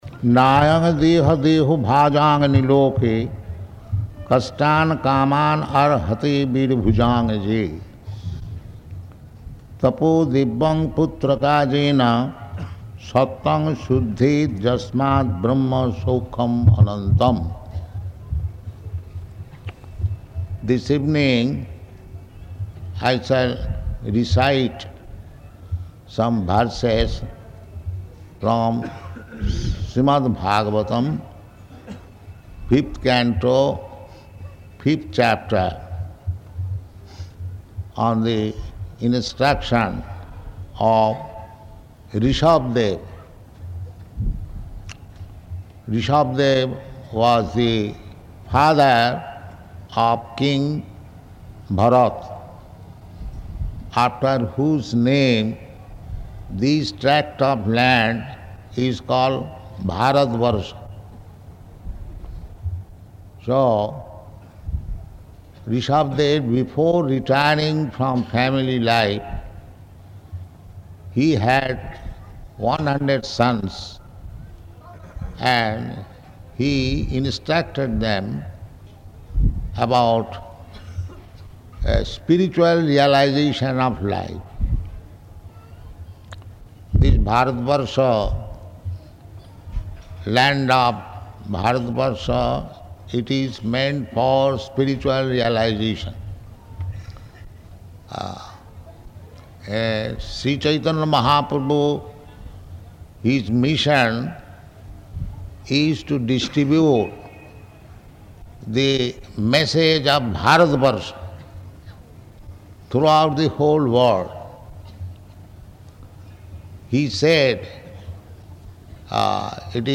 Location: Hyderabad